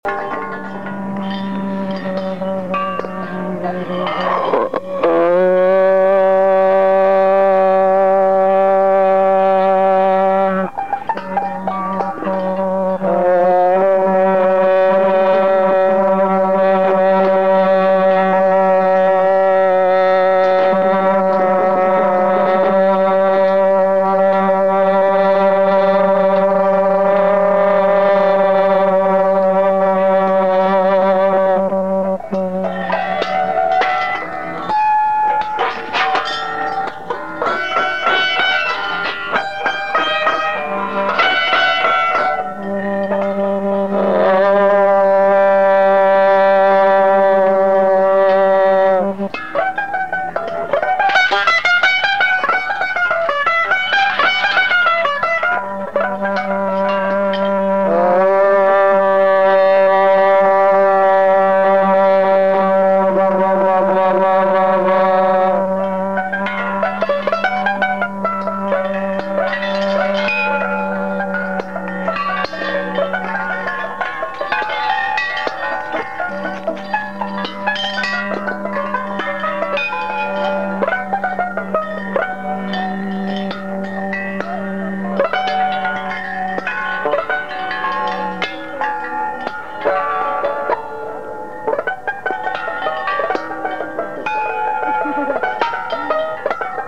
Location: Stevens Square/Red Hot Art